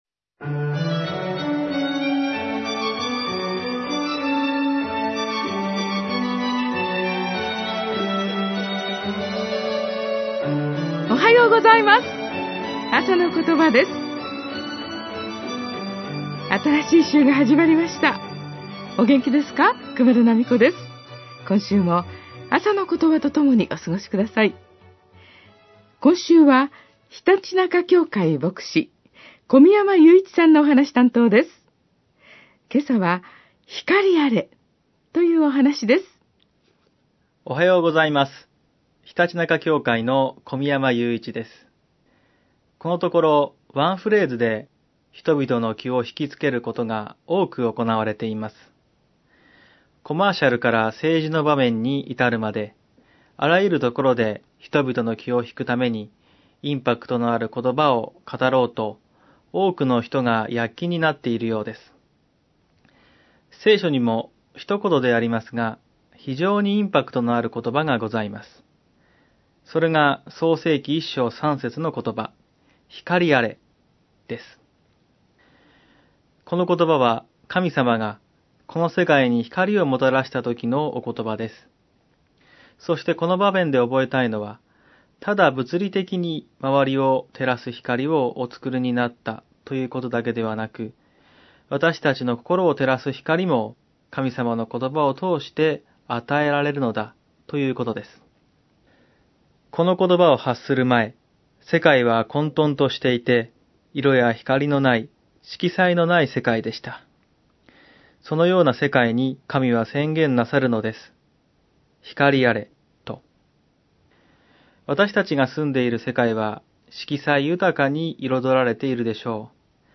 あさのことば 2015年1月4日（日）放送